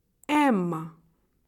En cliquant sur le symbole, vous entendrez le nom de la lettre.
lettre-m.ogg